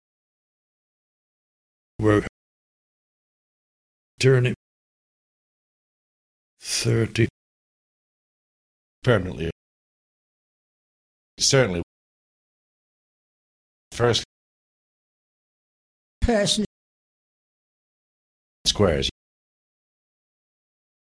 Rhotic informants born in the 1920s, 1930s and 1950s, and one nonrhotic informant born in the 1970s.
Listen to rhotic and nonrhotic examples from Preston and Blackburn:
Example 8: Rhotic NURSE and SQUARE vowels
blackburnrhoticnurse.mp3